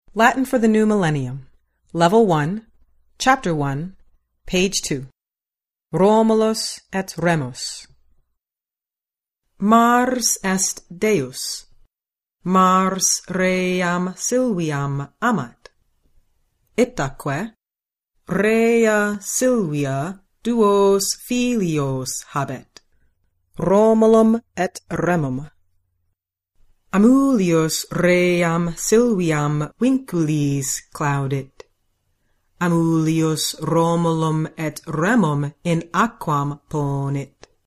provide a professionally recorded reading in the restored classical pronunciation of Latin.